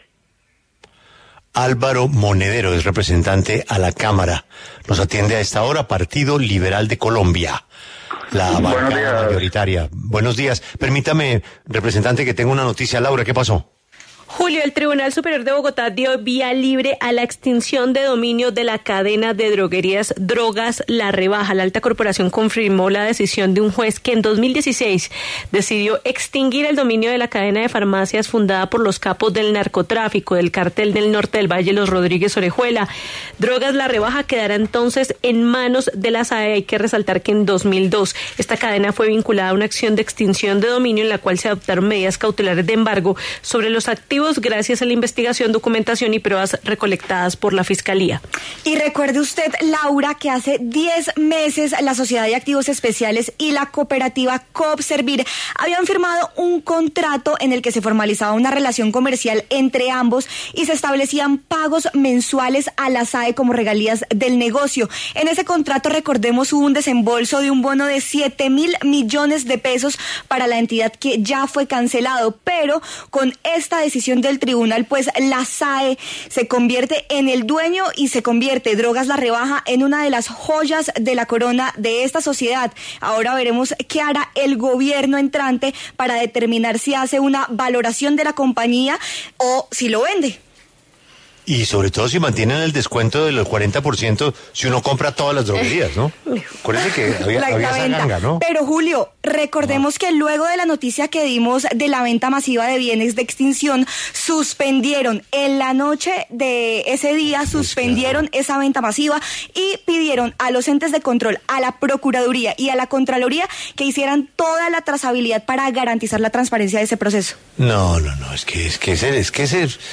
Álvaro Monedero, representante a la Cámara por el Partido Liberal, se pronunció en La W sobre el futuro del partido de cara al gobierno de Gustavo Petro.